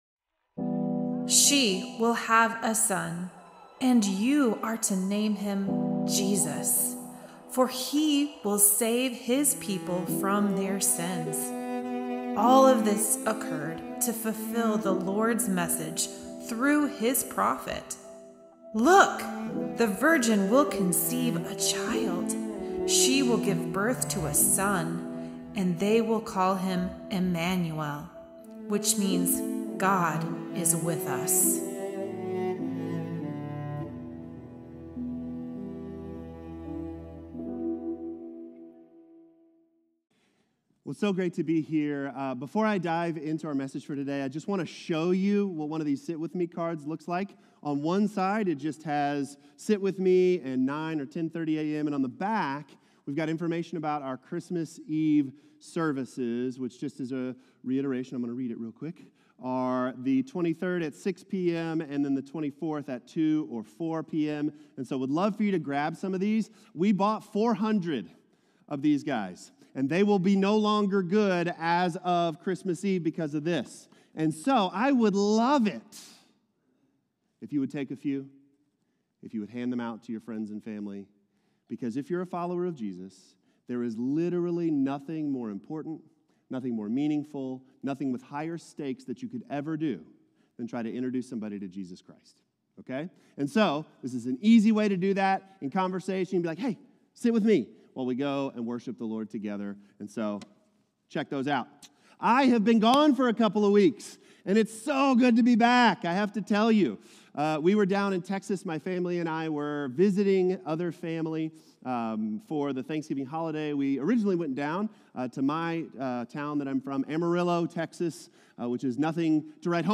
Hope-Sermon-12.8.24.m4a